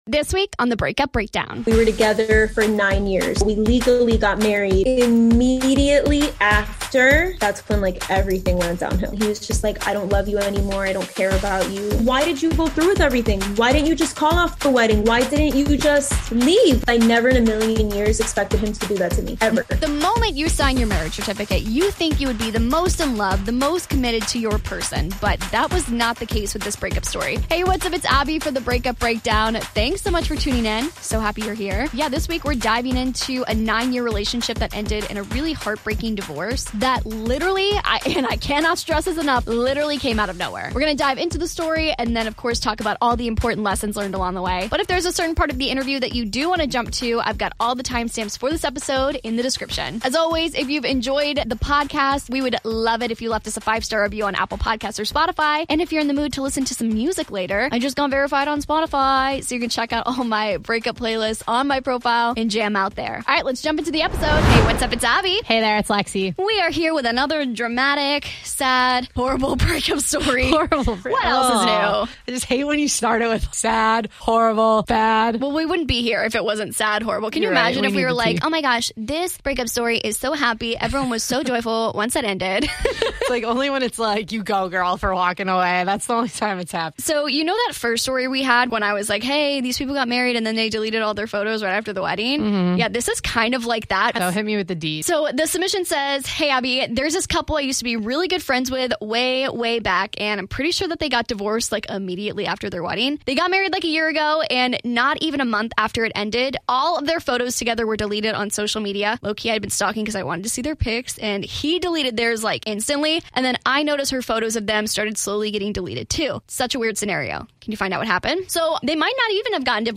Interview begins/how they met